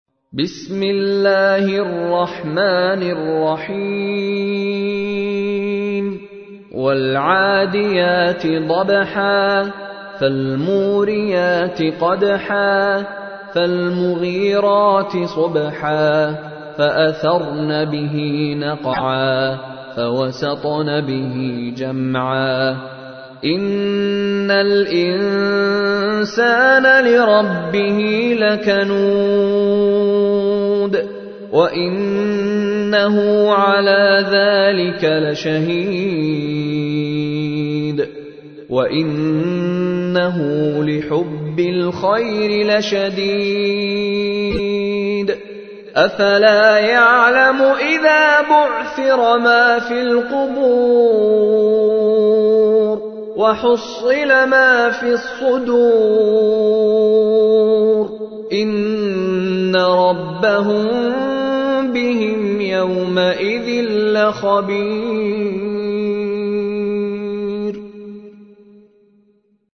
تحميل : 100. سورة العاديات / القارئ مشاري راشد العفاسي / القرآن الكريم / موقع يا حسين